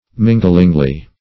minglingly - definition of minglingly - synonyms, pronunciation, spelling from Free Dictionary Search Result for " minglingly" : The Collaborative International Dictionary of English v.0.48: Minglingly \Min"gling*ly\, adv. In a mingling manner.